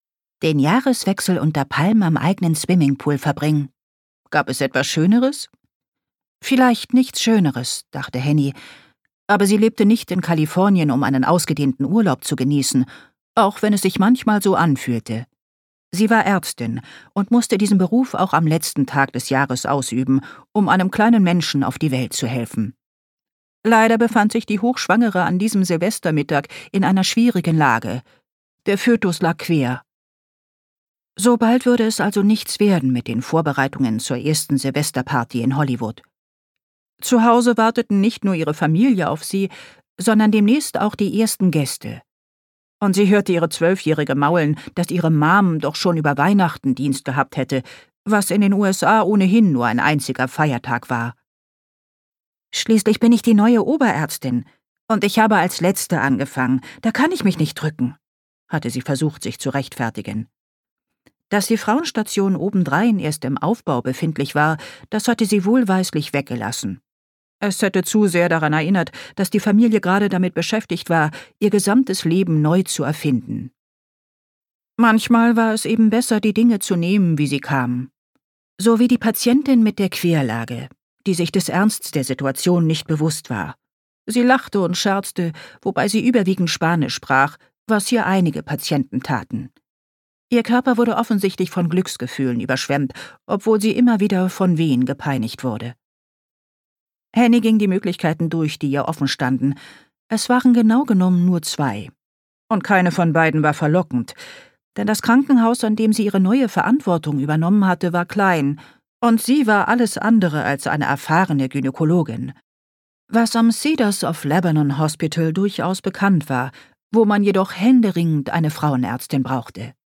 Hörbuch Print